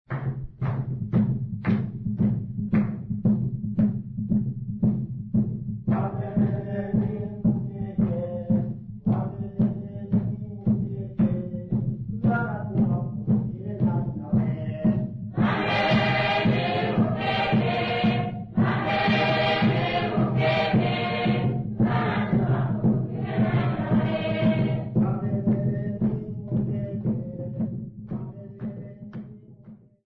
Tondoro church music workshop participants
Sacred music Namibia
Choral music Namibia
Ngoma (Drum) Namibia
Africa Namibia Tondoro, Okavango sx
field recordings
Church song with drum accompaniment.